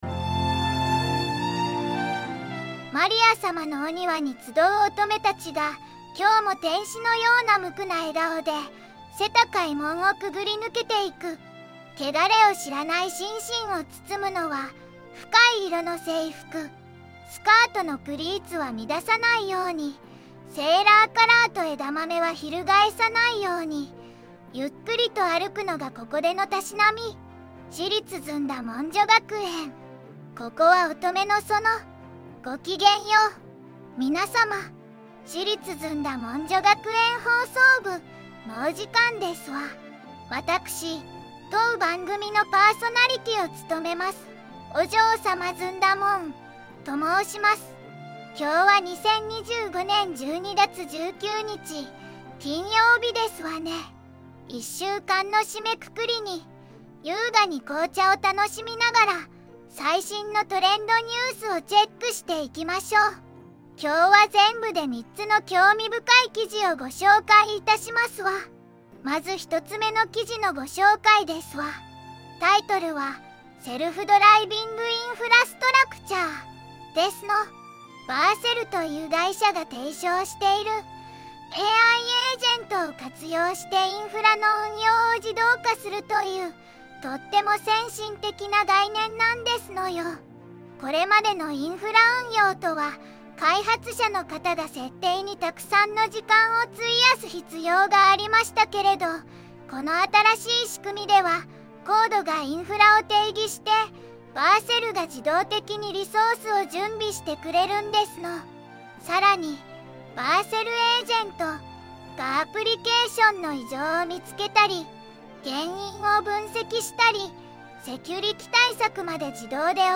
VOICEVOX:ずんだもん